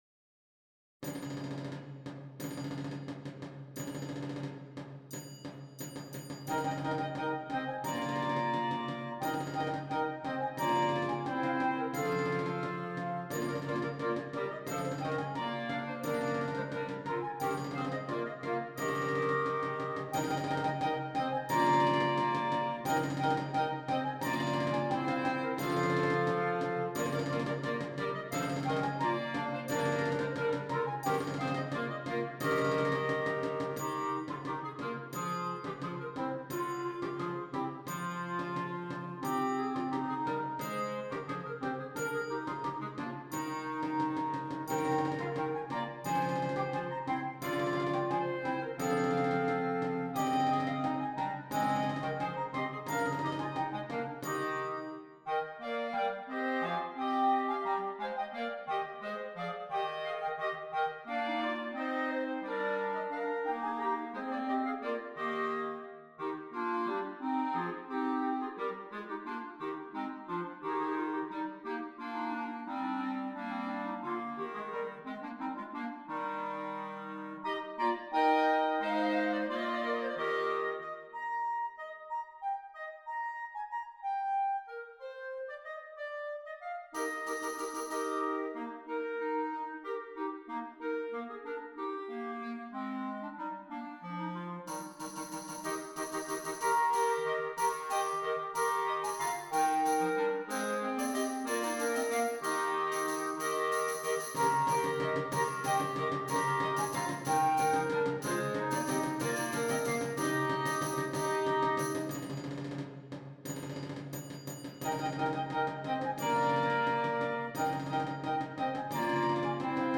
4 Clarinets - optional Percussion